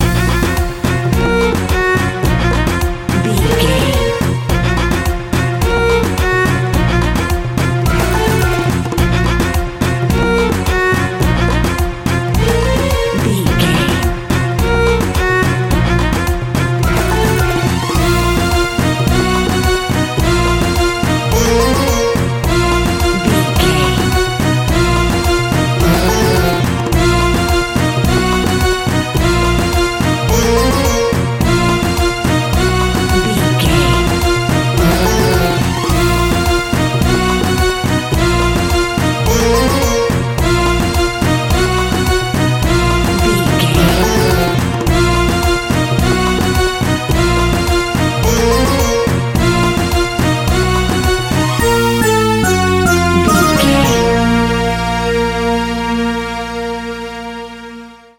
Aeolian/Minor
B♭
World Music
percussion